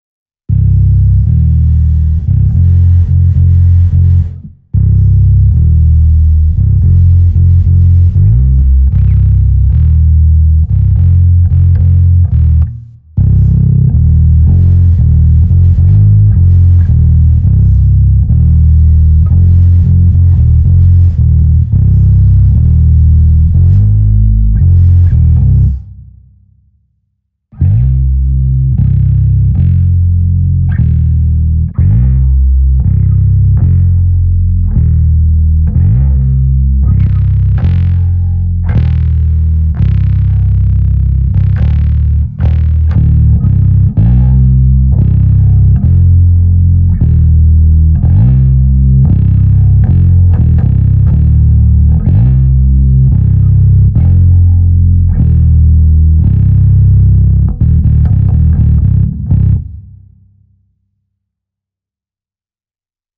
ich habe vorhin im proberaum, mal alles was geht hinein gedreht....das ganze mit mikros abgenommen: links: 115 rechts: 412 mitte: DI out in der reihenfolge. und danach dann jeweils 115 + 412 gemeinsam. klingt die 412 nicht doch bassfähig?